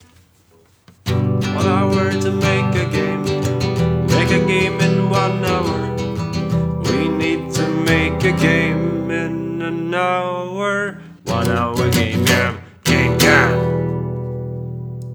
mastered version (louder, better sound)